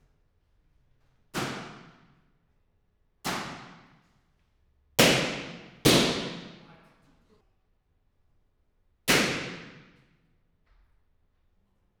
Palloncini-WY.WAV